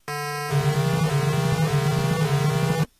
Descarga de Sonidos mp3 Gratis: alarma 14.